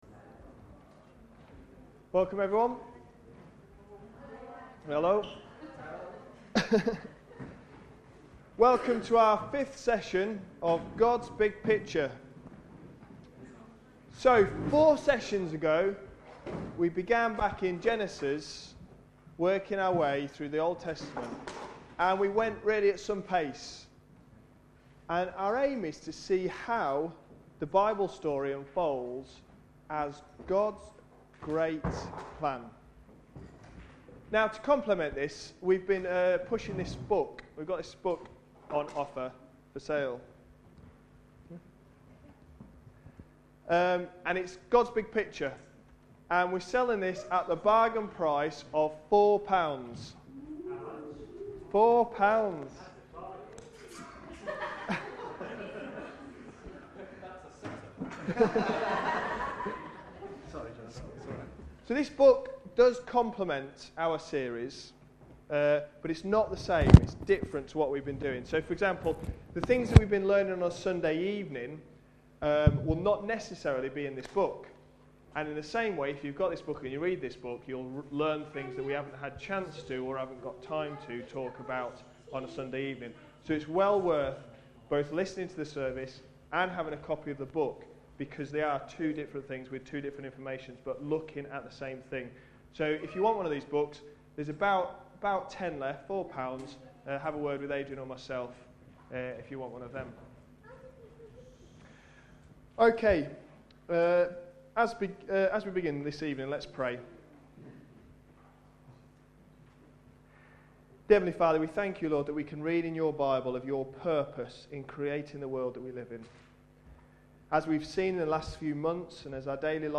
A sermon preached on 14th November, 2010, as part of our God's Big Picture series.